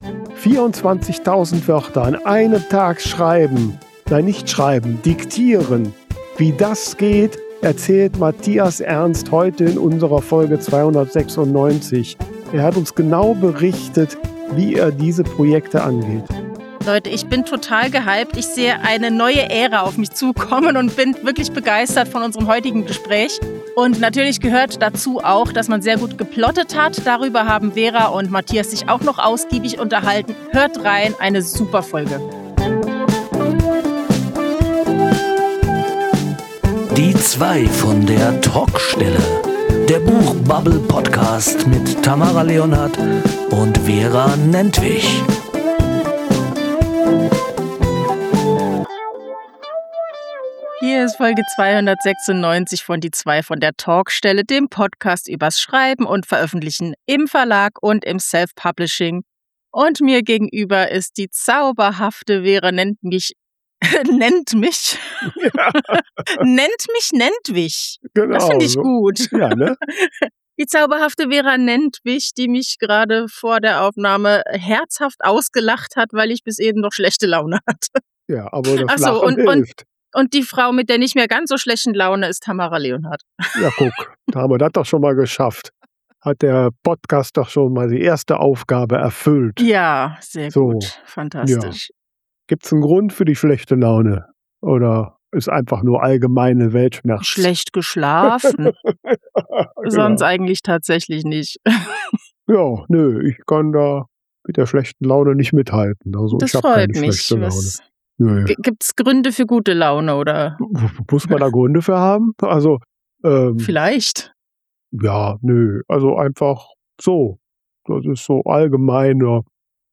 Gespräche über das Schreiben und Veröffentlichen von Büchern, egal ob Selfpublishing oder Verlag.